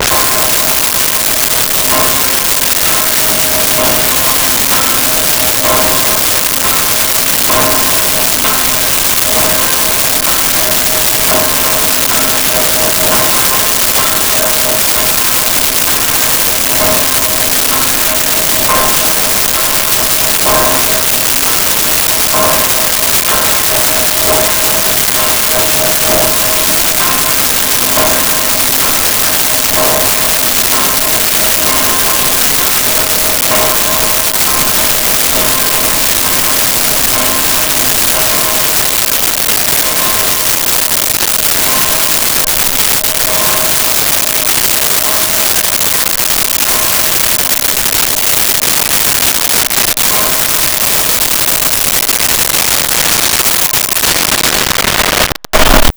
Church Bells And Birds
Church Bells And Birds.wav